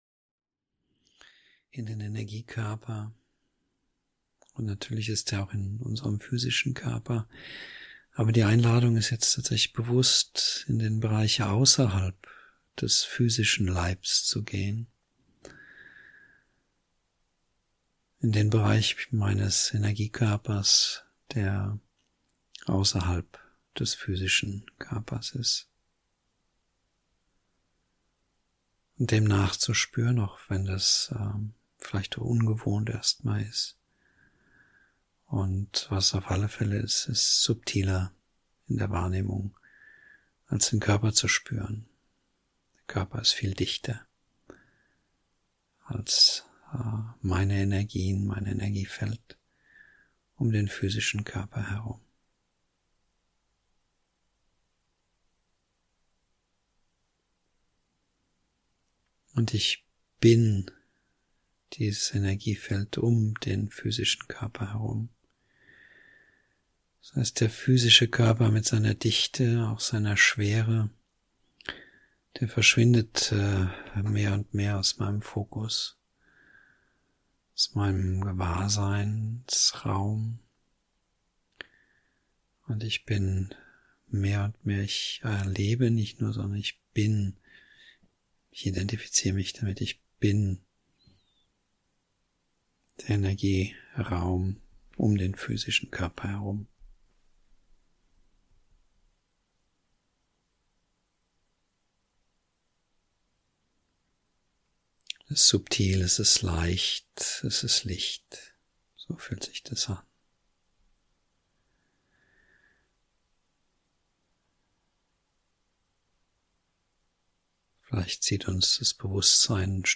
02 Gewahrseinsebenen transzendenten Bewusstseins (Meditation)   27min